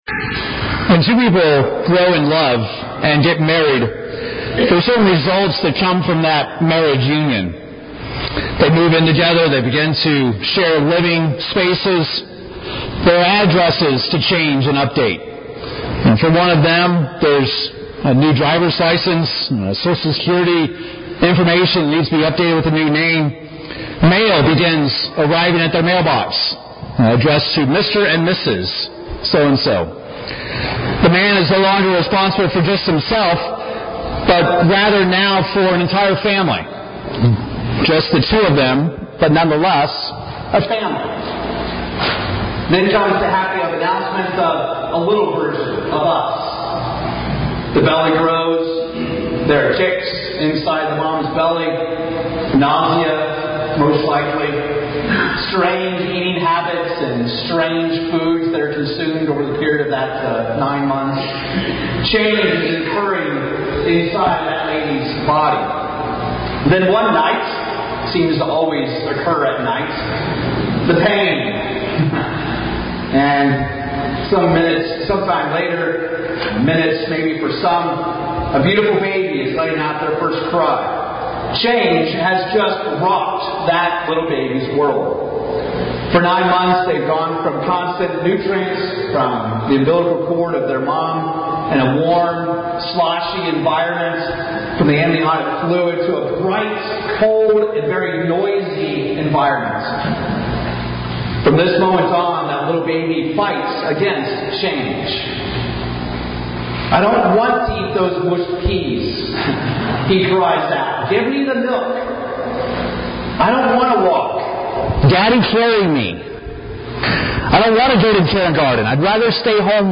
In this Feast of Pentecost message, we'll see that God has called you to change – to transform yourself to Him – in order to be a Firstfruit.
Given in Oklahoma City, OK
UCG Sermon Studying the bible?